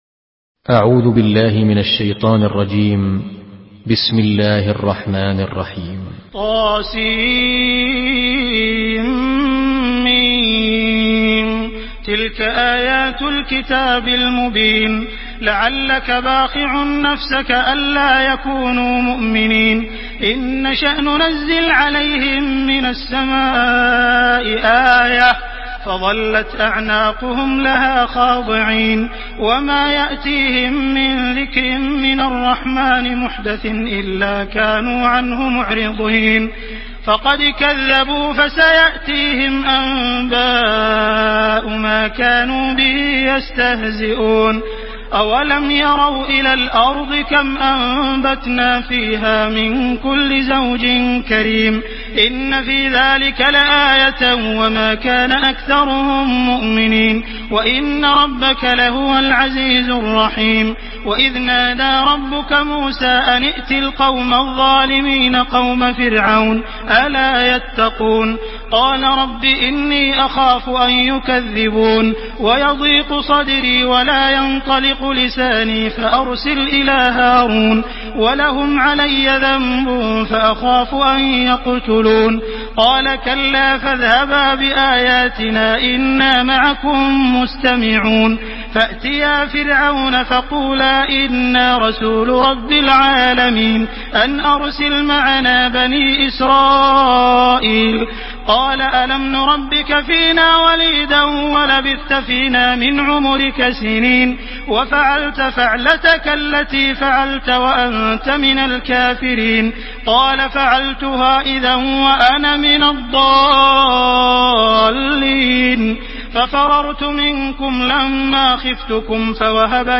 Surah الشعراء MP3 in the Voice of عبد الرحمن السديس in حفص Narration
مرتل حفص عن عاصم